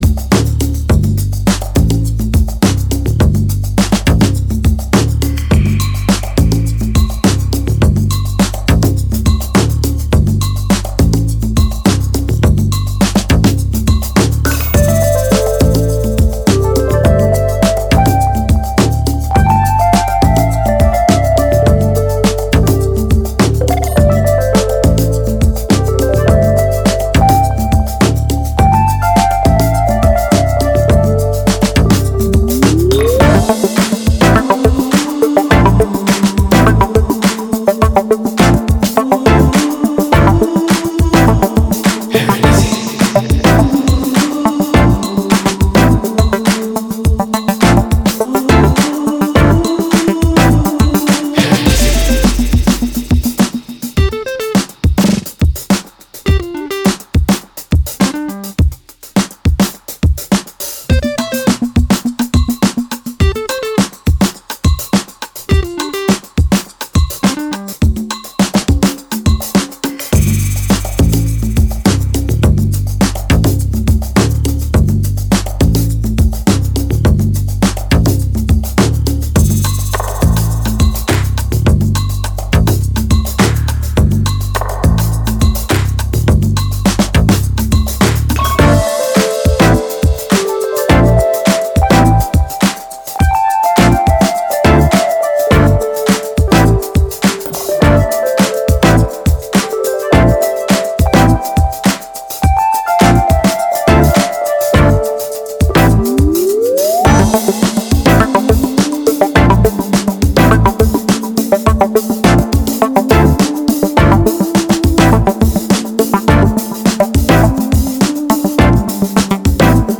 boogie with a South American shuffle